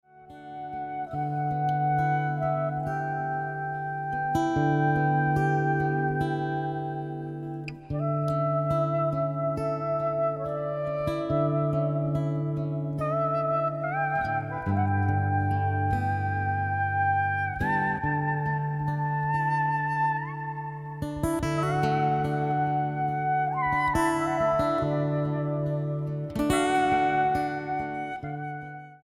Style: World Approach: Praise & Worship